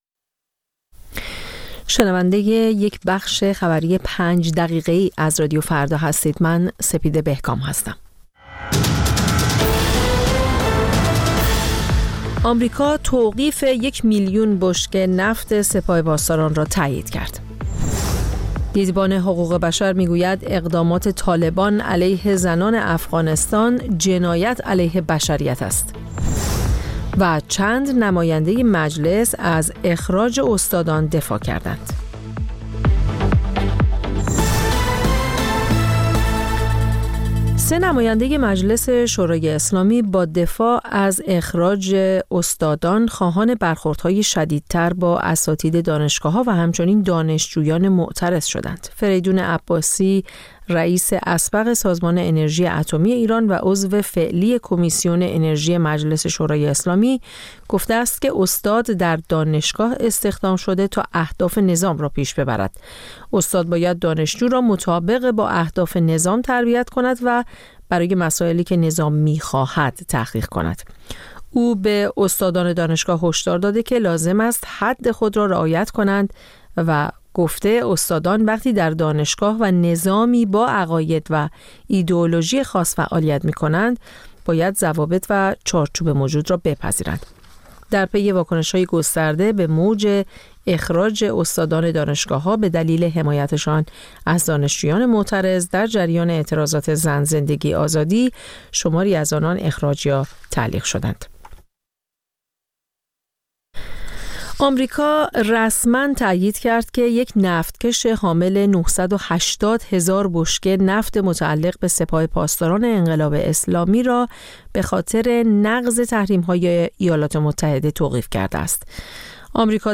همچون هر روز، مجله نیمه‌شب رادیو فردا، تازه‌ترین خبرها و مهم‌ترین گزارش‌ها را به گوش شما می‌رساند.